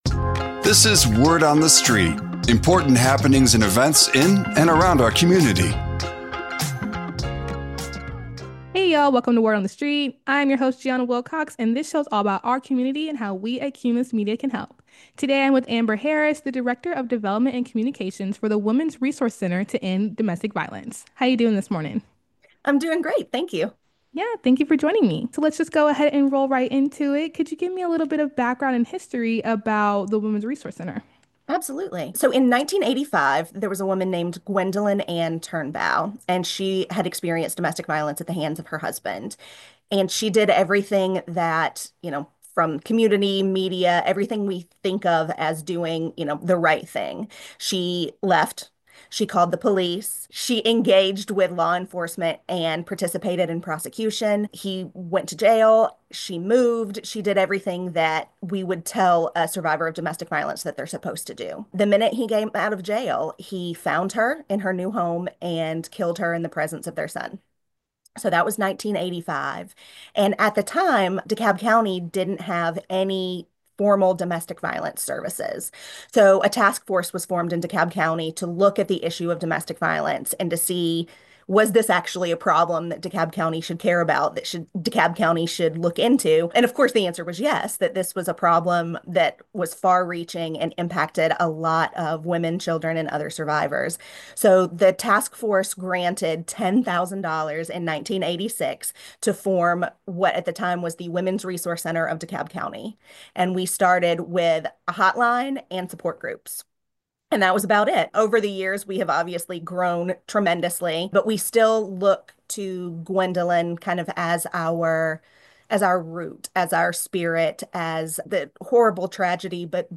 🎧 Listen to the full interview, which aired on Q99.7, New Country 101.5, 99X, and OG97.9